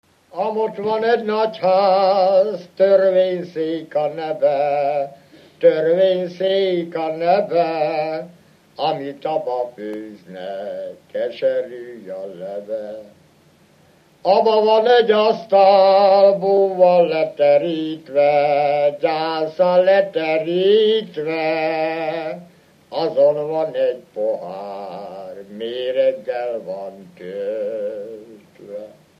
Felföld - Gömör és Kishont vm. - Dernő
ének
Műfaj: Rabének
Stílus: 1.1. Ereszkedő kvintváltó pentaton dallamok